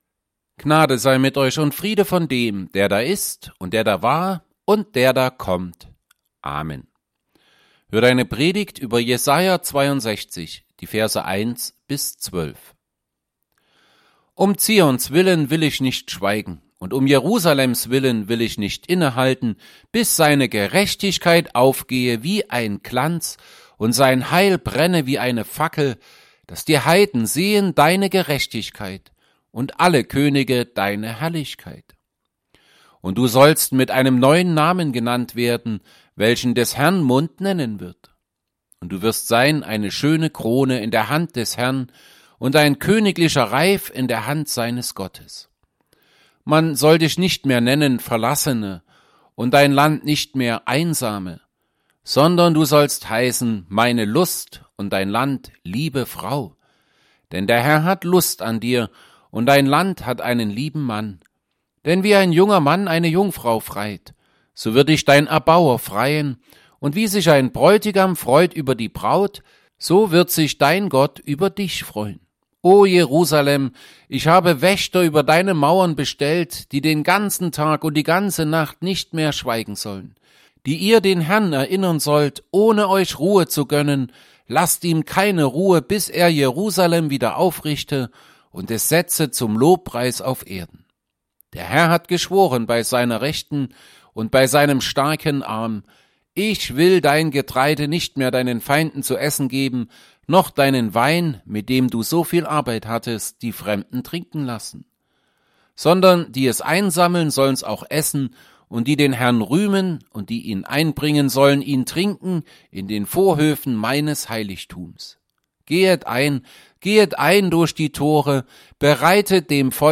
Predigt_zu_Jesaja_62_1b12.mp3